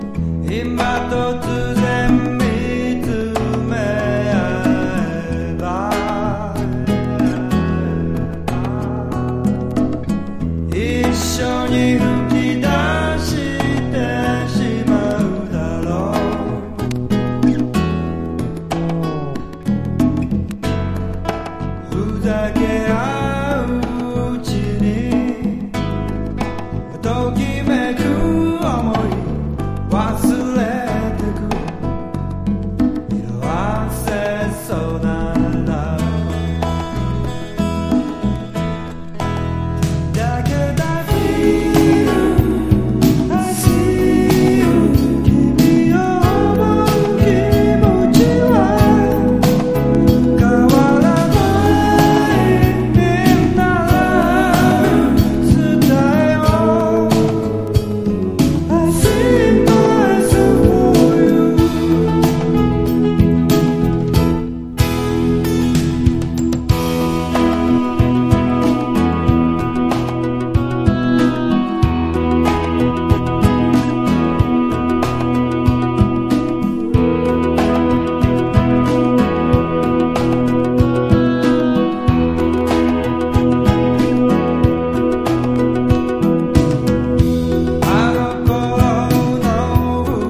美しいファルセットと太く甘い地声を使い分け、A2のようなSteely DanテイストのAORを展開。
CITY POP / AOR
ポピュラー# 和モノ / レアグルーヴ